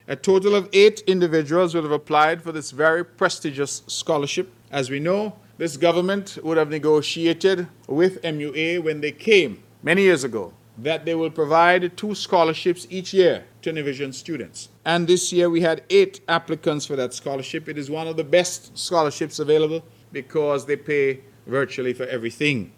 Premier of Nevis the Hon. Mark Brantley gives more details:
The award ceremony was held at the Ministry of Gender Affairs conference room.